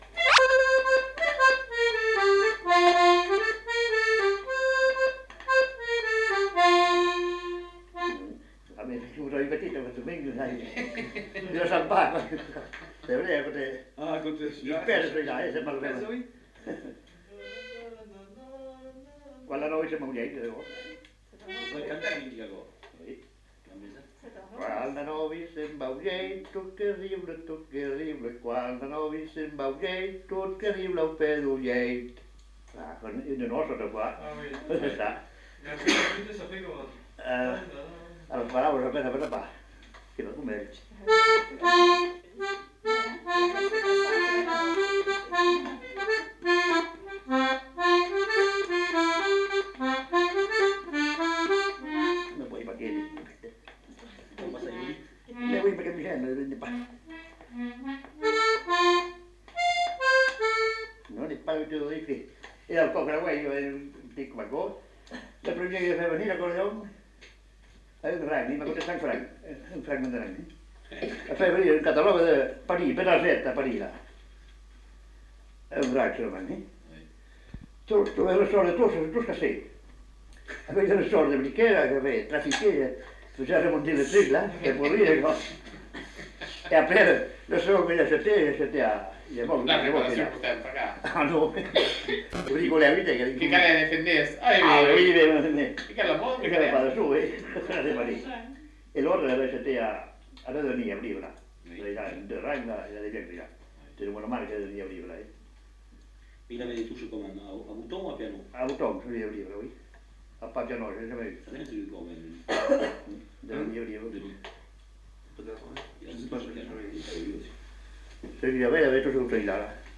Aire culturelle : Petites-Landes
Lieu : Lencouacq
Genre : morceau instrumental
Instrument de musique : accordéon diatonique
Danse : rondeau
Notes consultables : L'interprète a du mal à interpréter le morceau à l'accordéon mais il en chante un couplet.